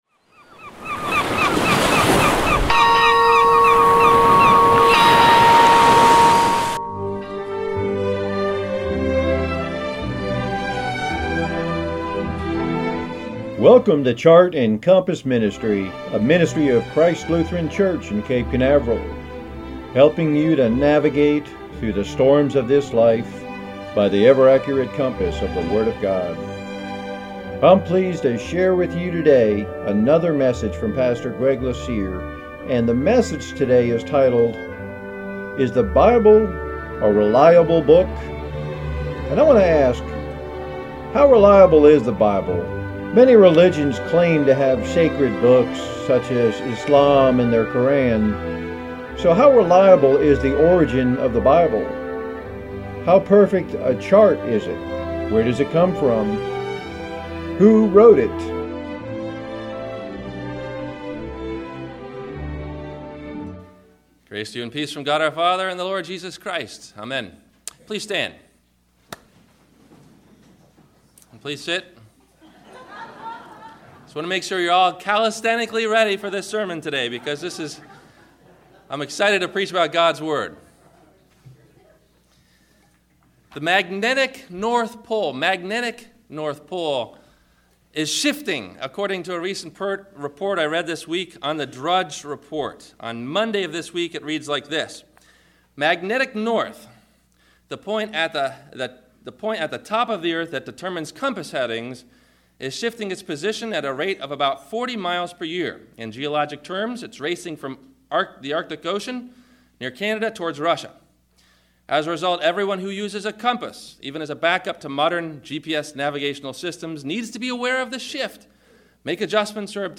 Is the Bible a Reliable Book? – WMIE Radio Sermon – November 23 2015